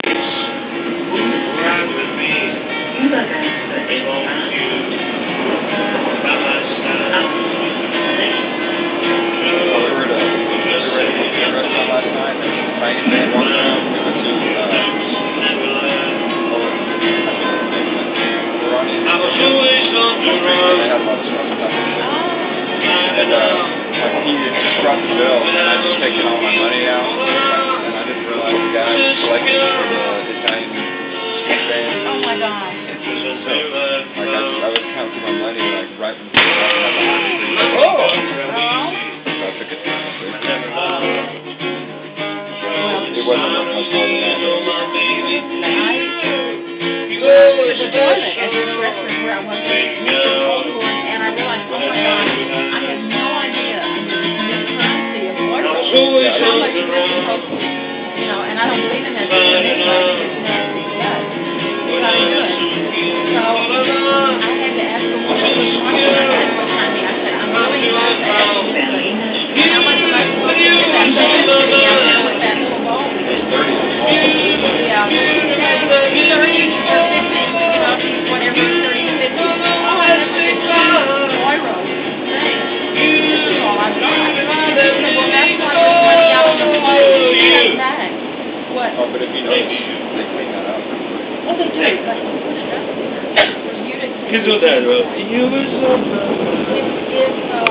U-Bahn-Musiker / Subway Musician
Dieser Musiker hat uns auf der U-Bahn mit seiner Musik angegriffen. / This musician attacked us on the subway with his music.